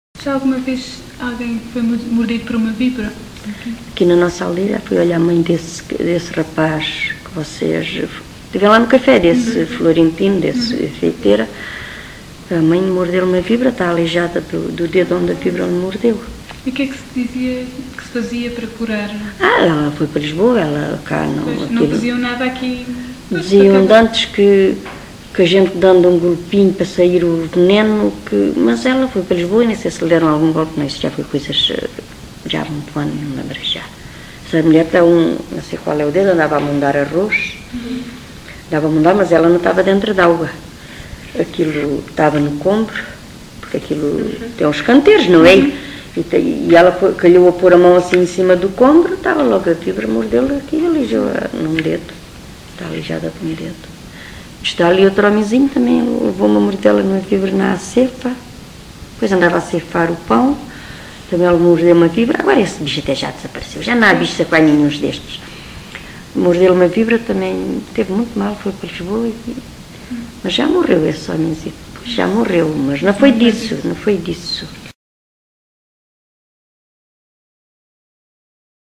LocalidadeSanta Justa (Coruche, Santarém)